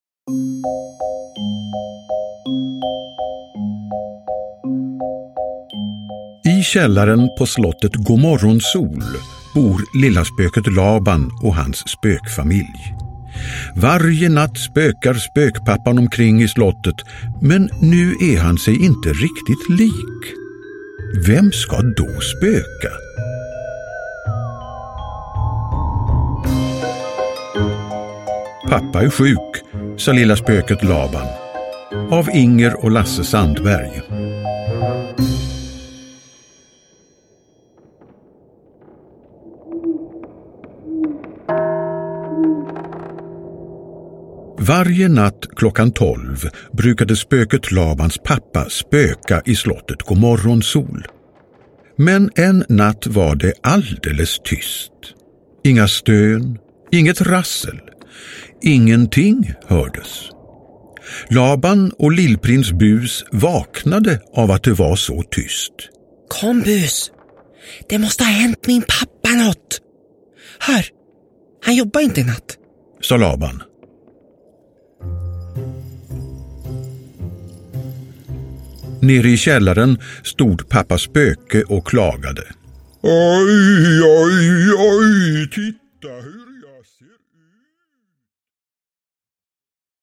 Pappa är sjuk, sa lilla spöket Laban – Ljudbok – Laddas ner